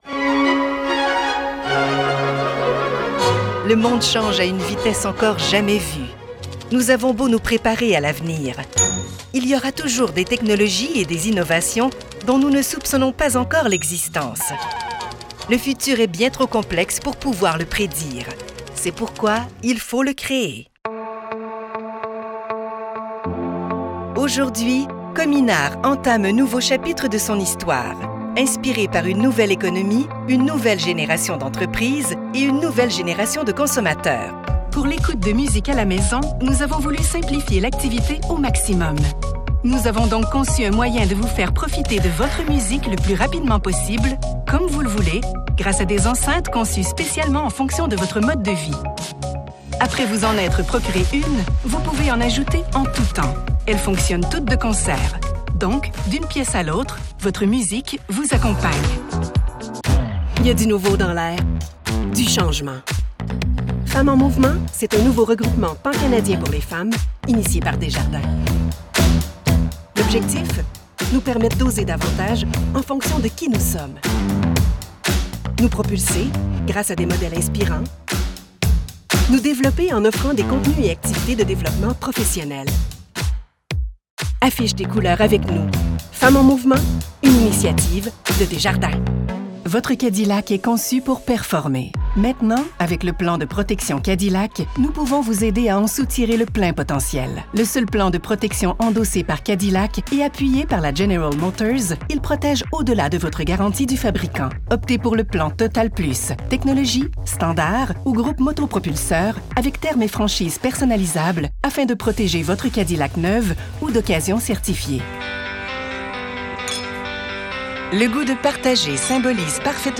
Seasoned and Global French Canadian Voice Artist
Showcase corporate voices
Business product presentation
Quebec accent or normative French Canadian
My voice has “oomph”, a unique grain and a funky edge that adds punch; making it catchy, reassuring, sensitive and true all at once.